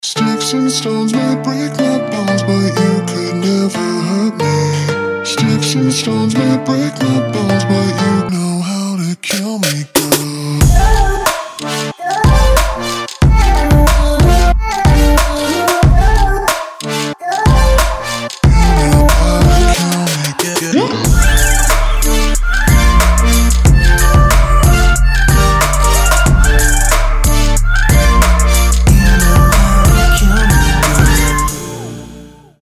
• Качество: 320, Stereo
Electronic
future bass